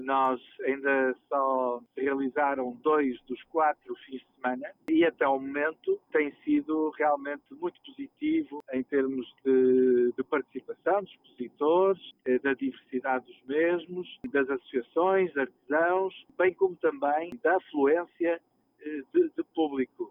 Segundo o autarca local, Pedro Lima, a avaliação global será feita apenas no final do certame, porém, os primeiros indicadores são bastante favoráveis: